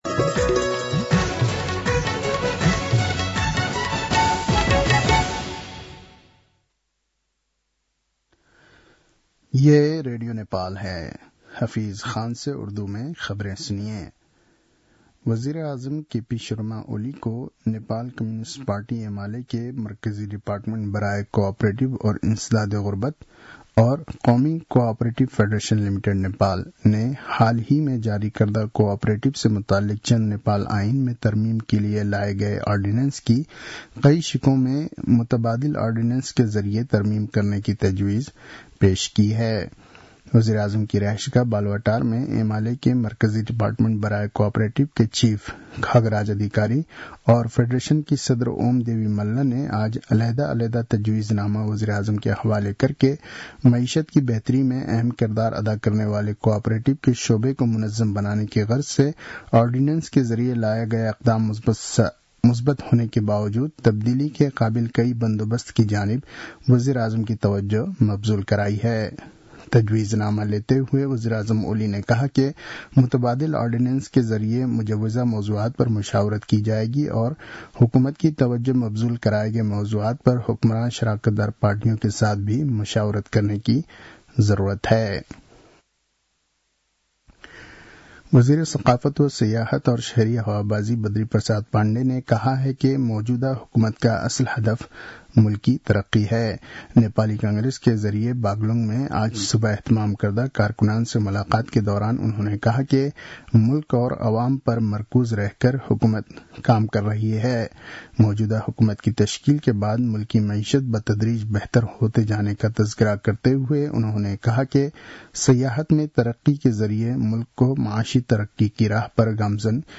उर्दु भाषामा समाचार : २० माघ , २०८१